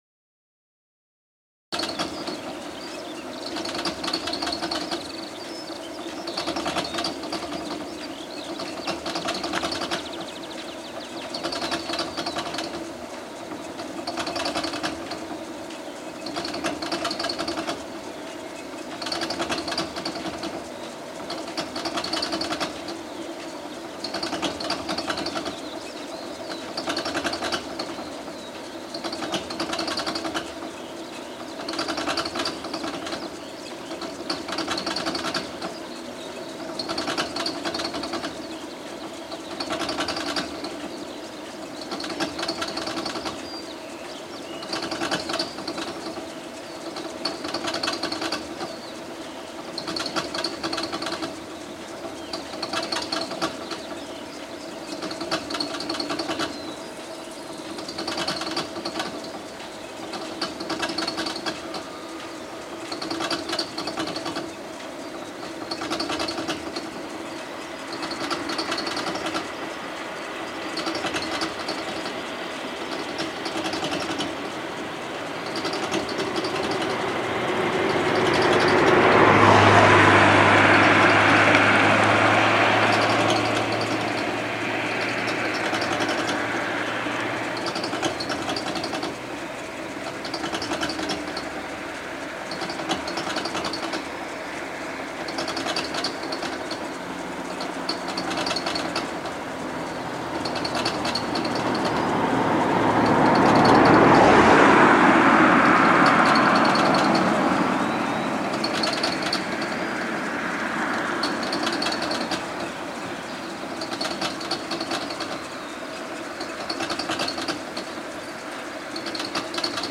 Field irrigation on a farm in Beaufort, France (Audio 32)
A country road in Beaufort, France located in the Loire Valley.
field-irrigation.mp3